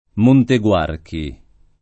Monteguarchi [ monte gU# rki ] → Montevarchi